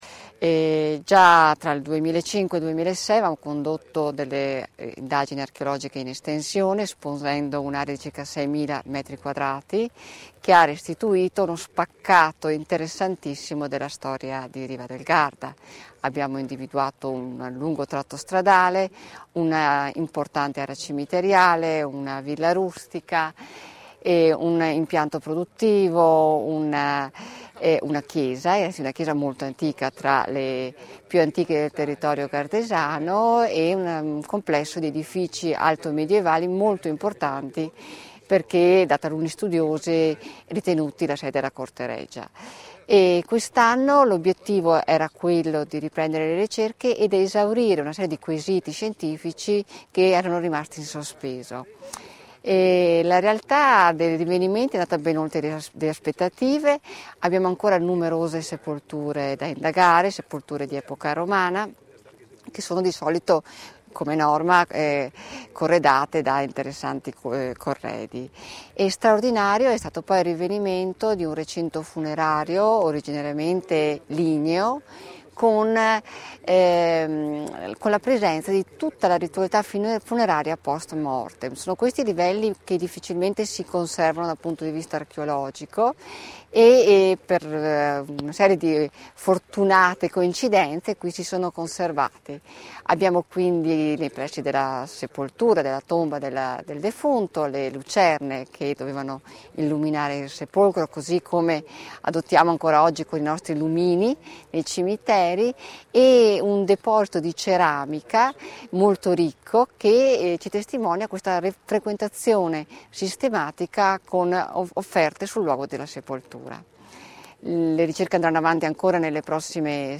Poco fa a Riva del Garda la presentazione
INTERVISTA_SAN_CASSIANO_(1).mp3